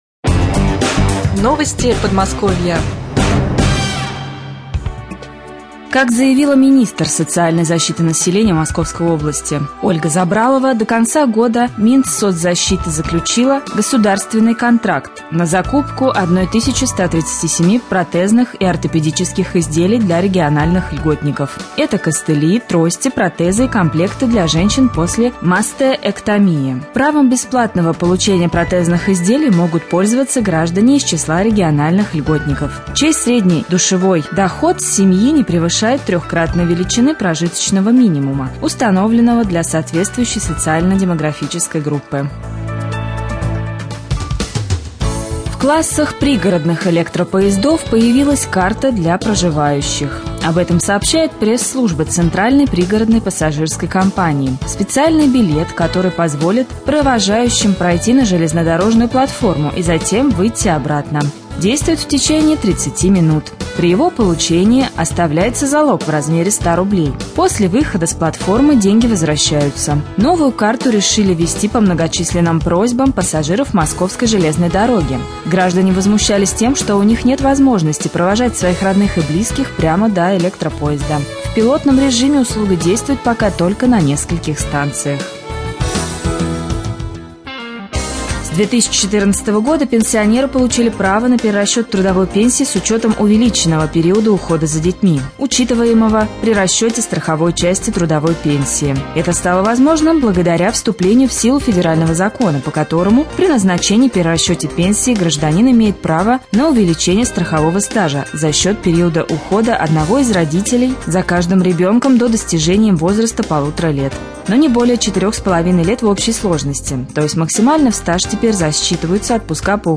1.Новости-Подмосковья.mp3